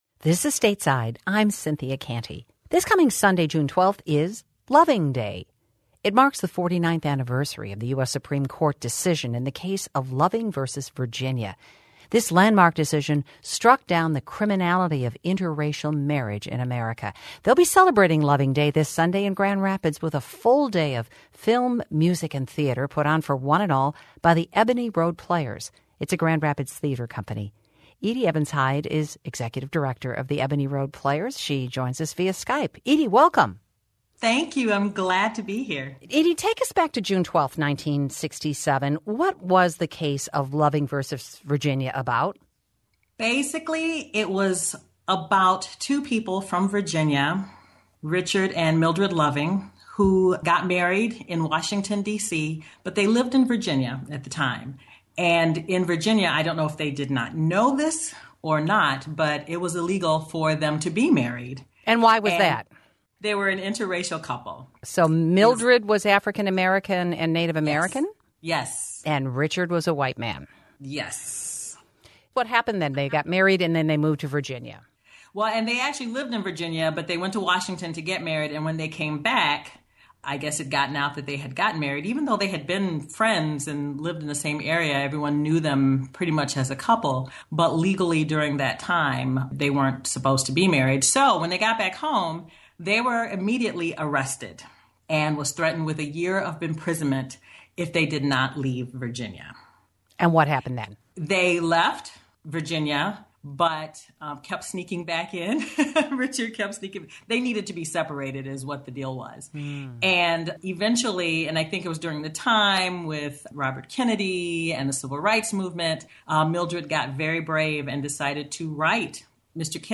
Guest